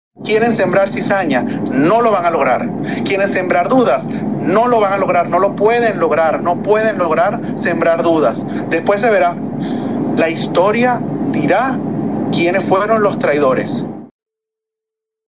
Por otra parte, en redes sociales, Nicolás Maduro Guerra, “Nicolasito”, reaccionó a la captura de su padre y llamó a la movilización.